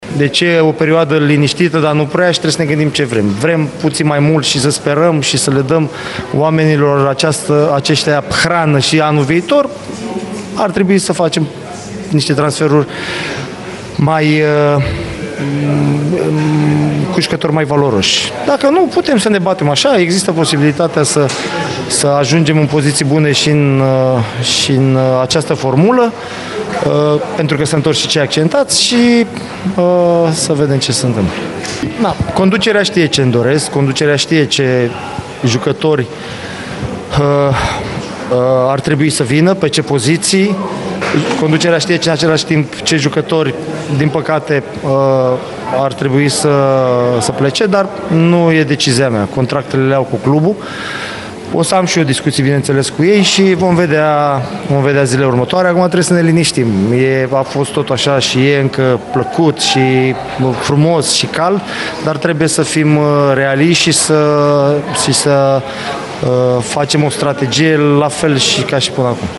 Tehnicianul arădenilor nu a ocolit nici o discuţie legată de transferuri în iarnă, pentru ca echipa sa să lupte, în continuare, la play-off: